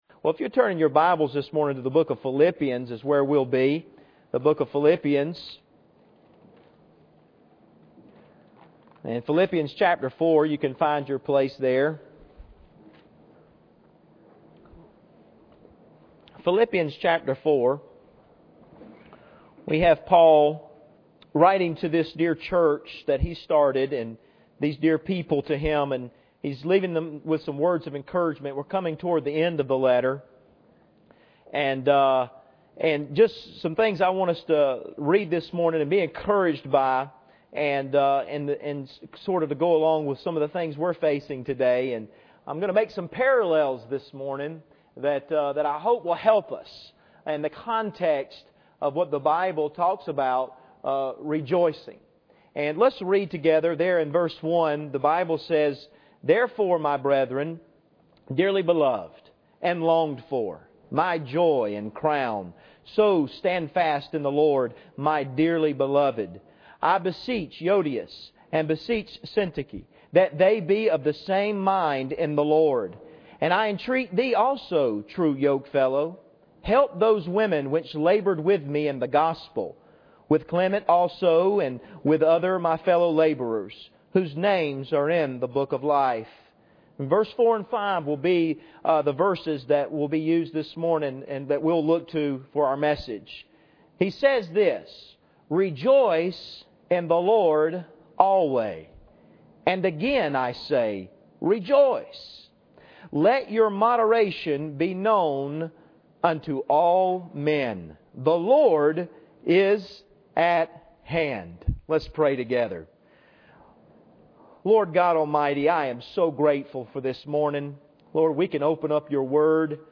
Philippians 4:1-5 Service Type: Sunday Morning Bible Text